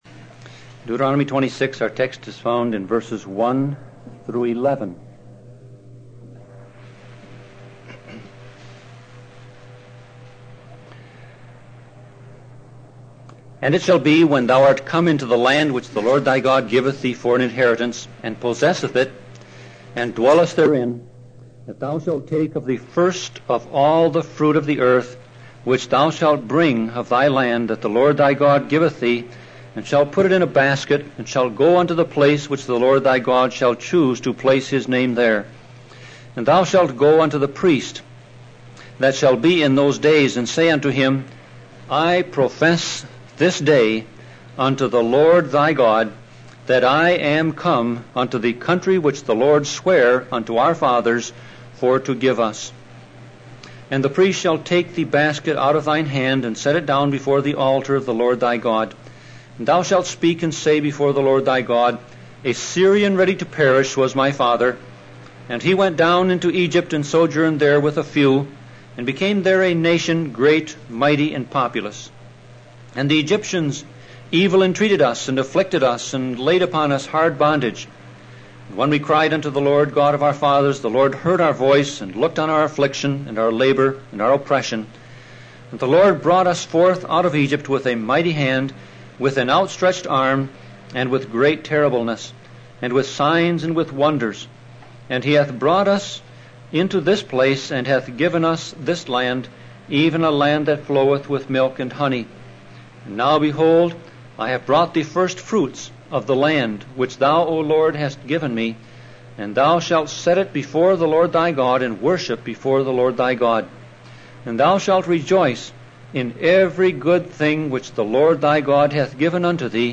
Sermon Audio Passage: Deuteronomy 26:1-11 Service Type